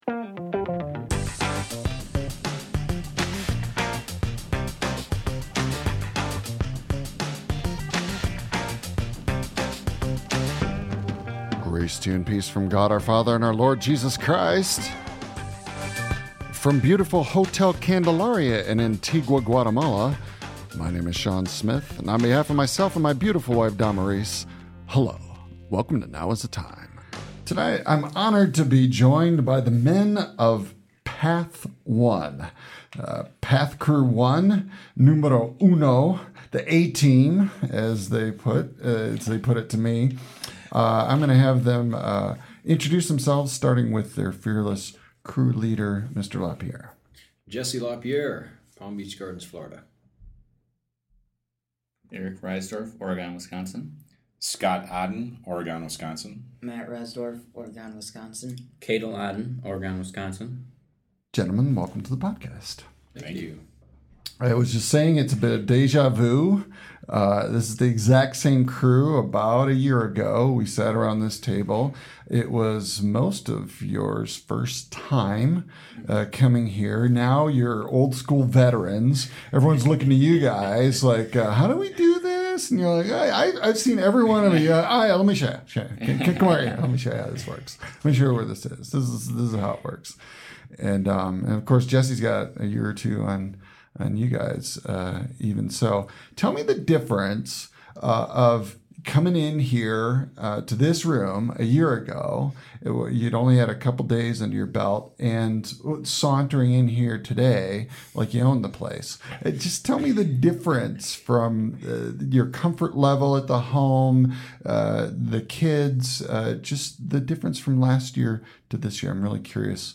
interviews members of Path Crew 2 from Week 1 at Hogar Miguel Magone from June 15-22, 2014.